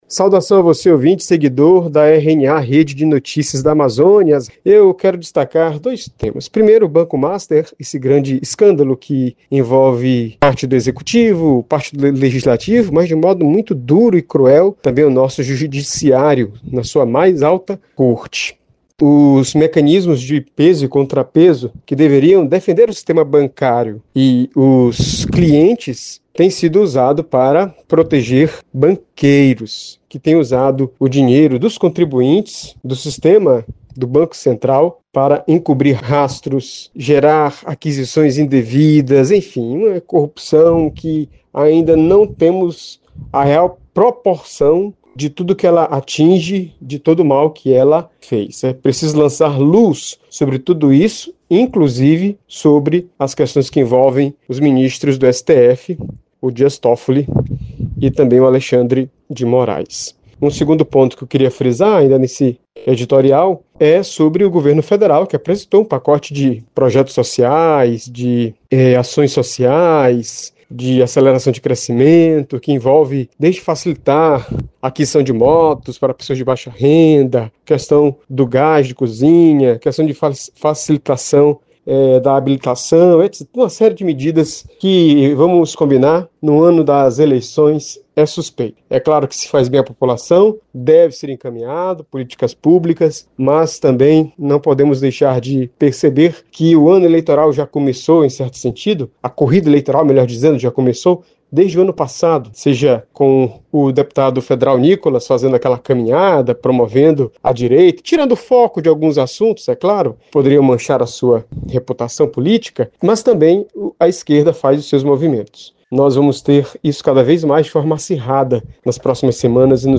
Acompanhe o editorial